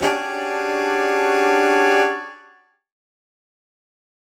UC_HornSwellAlt_Dmaj7b5.wav